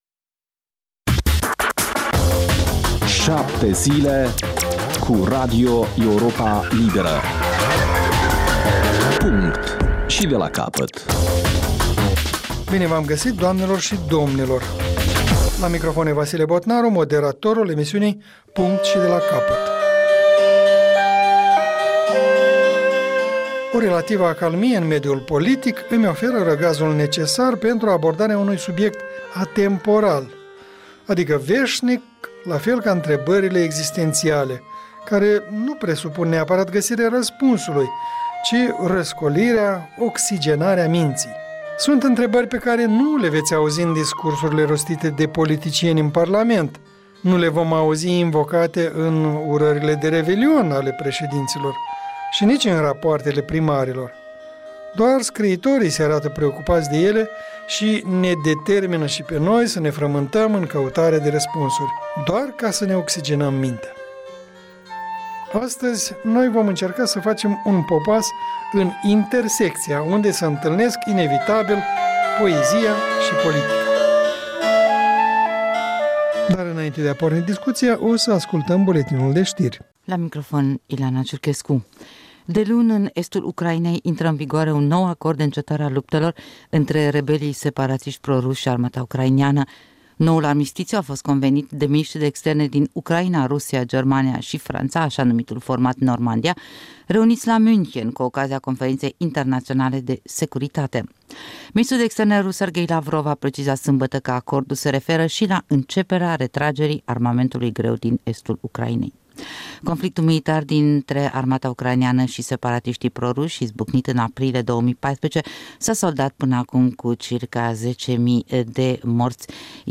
O discuţie la masa rotundă